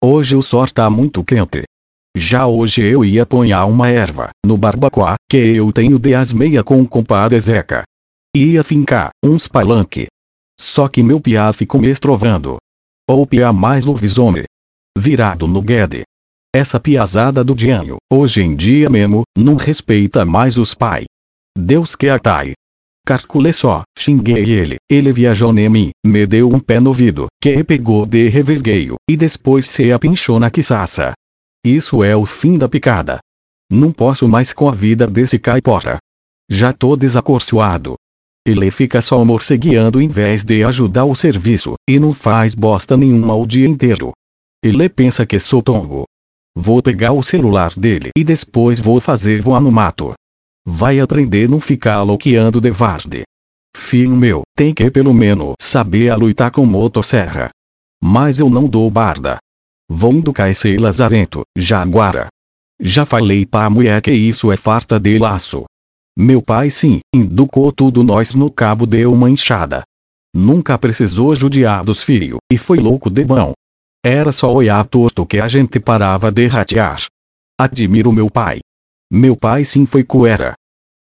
Google Tradutor do Dialeto Paranaense